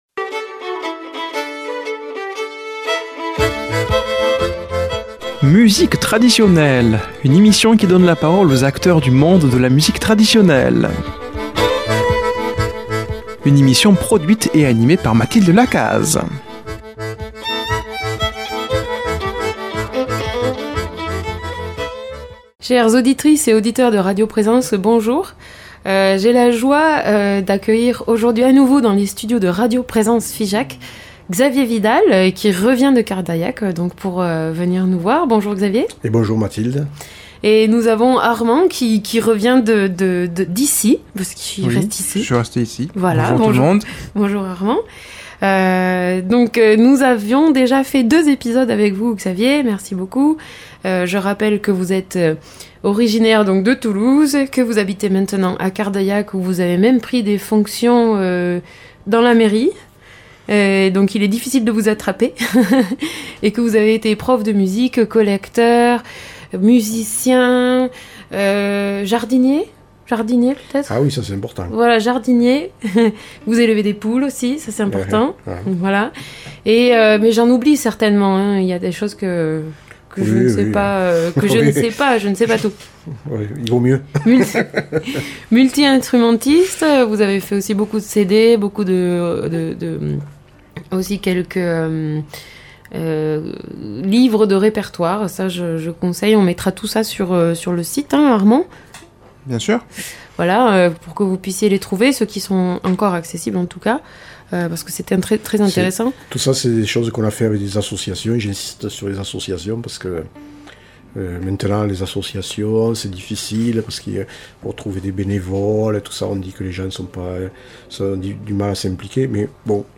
l'entretien avec une personnalité de la musique traditionnelle